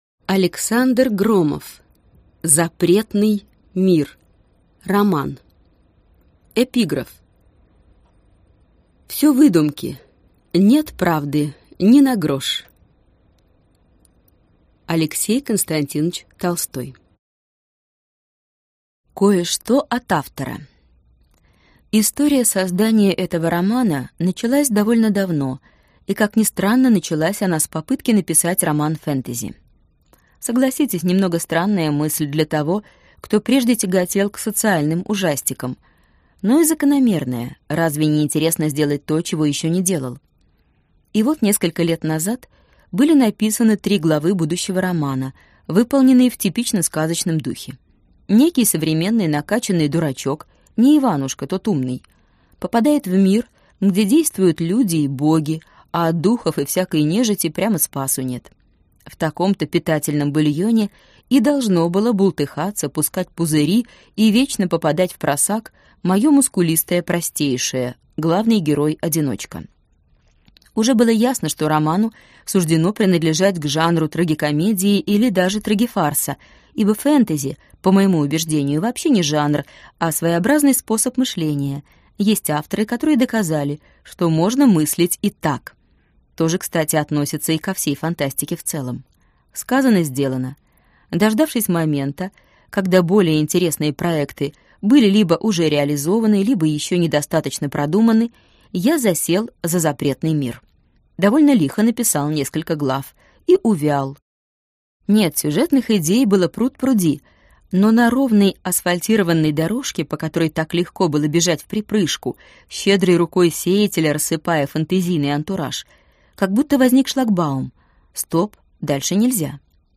Аудиокнига Запретный мир | Библиотека аудиокниг